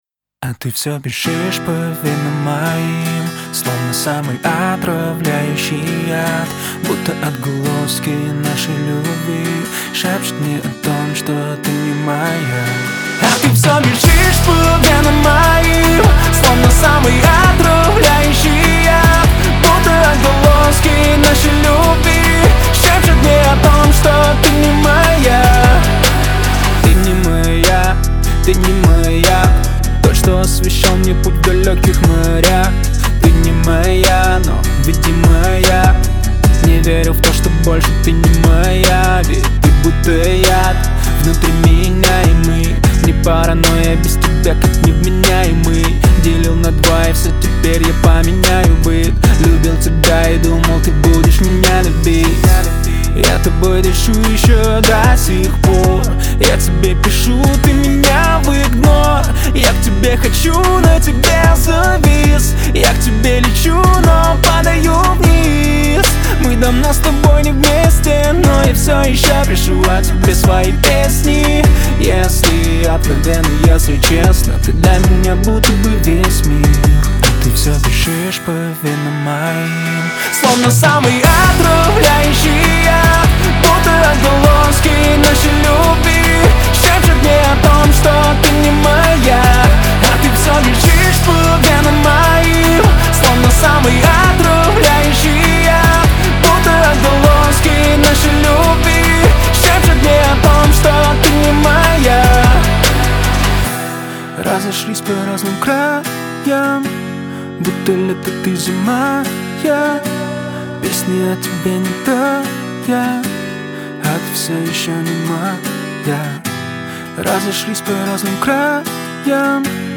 это мощный трек в жанре альтернативного рока